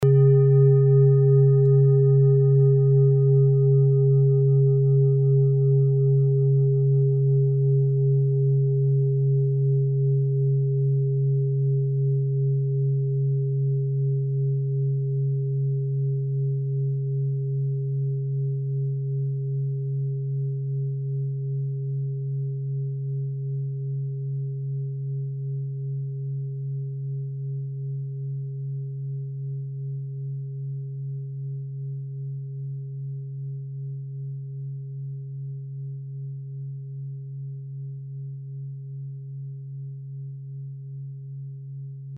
Klangschale TIBET Nr.38
Klangschale-Gewicht: 2170g
Klangschale-Durchmesser: 26,2cm
Sie ist neu und ist gezielt nach altem 7-Metalle-Rezept in Handarbeit gezogen und gehämmert worden.
(Ermittelt mit dem Filzklöppel oder Gummikernschlegel)
klangschale-tibet-38.mp3